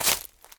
footsteps
decorative-grass-01.ogg